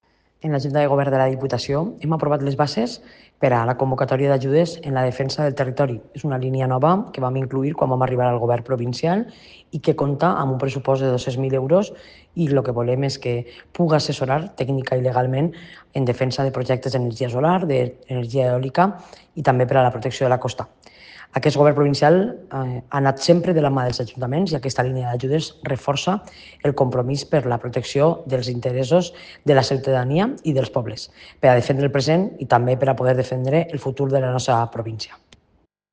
Presidenta-Marta-Barrachina-Ayudas-defensa-del-territorio-.mp3